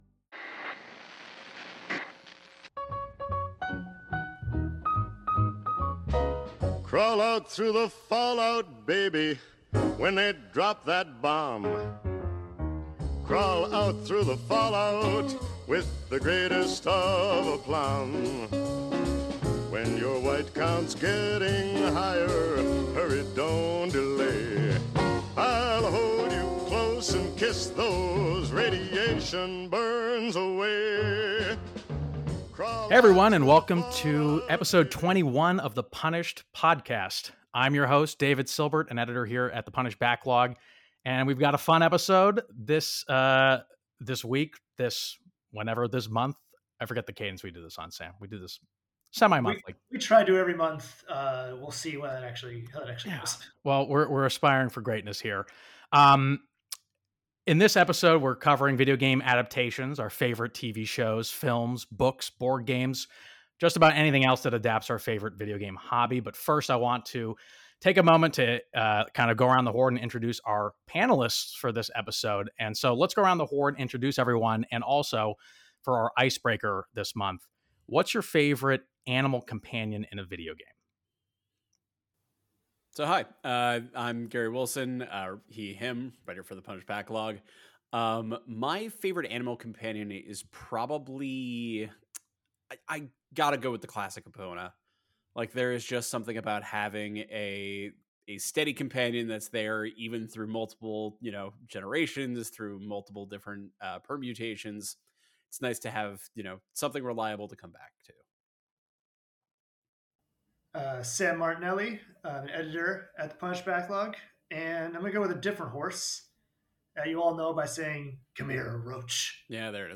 Apple Podcasts Note: This episode contains swearing and sexual references.